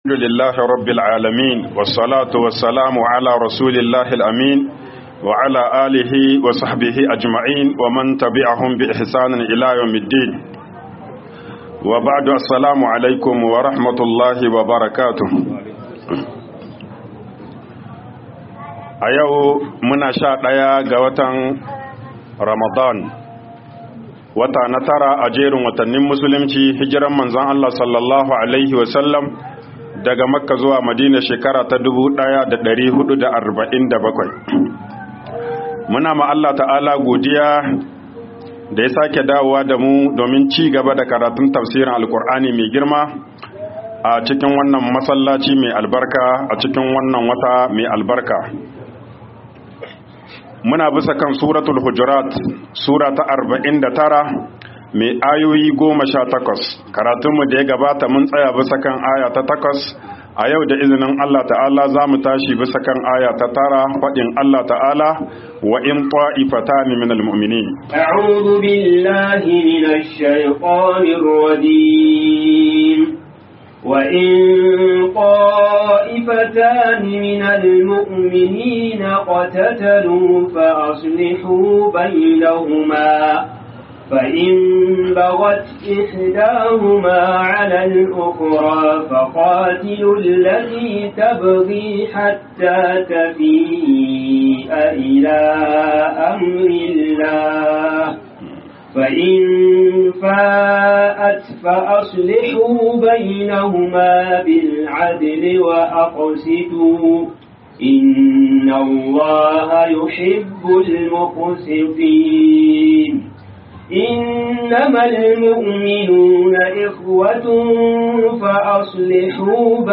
TAFSIR RAMADAN MASJID ALSHABAB 09